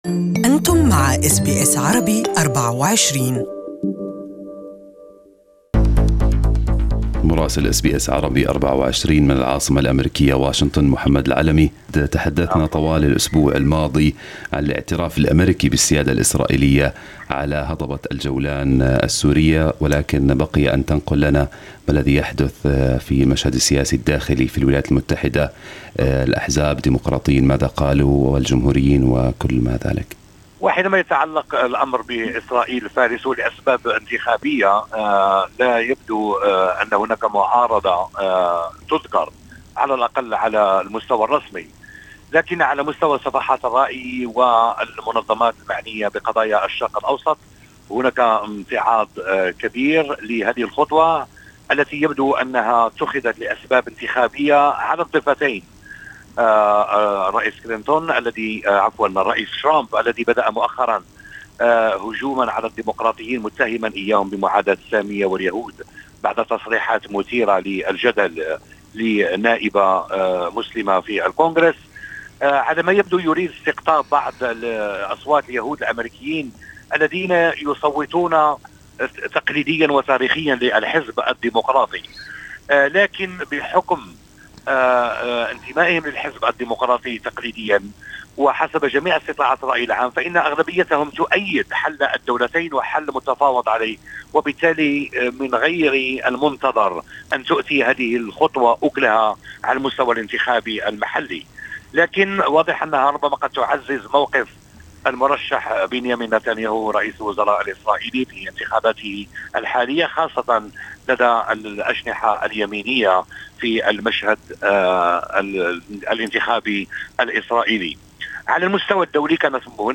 Our correspondent in Washington has the details